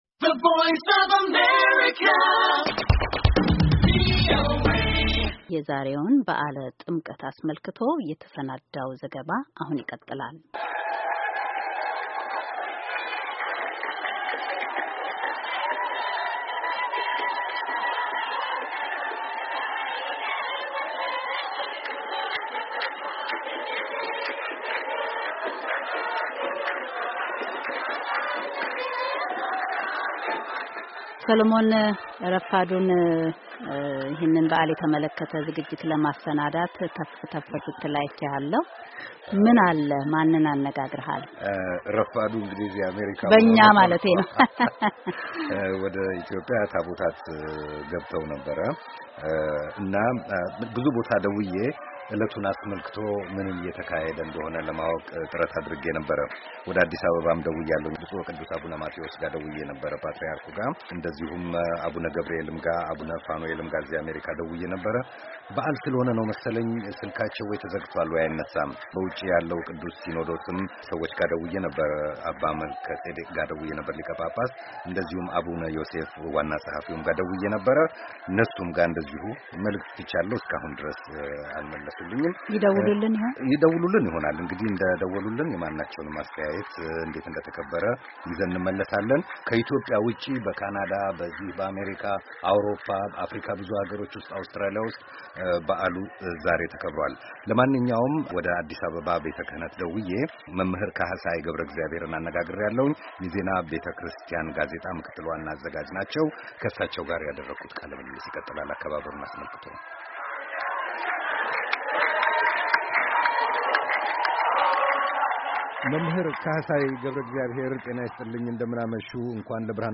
Epiphany 2015 in Ethiopia. Interview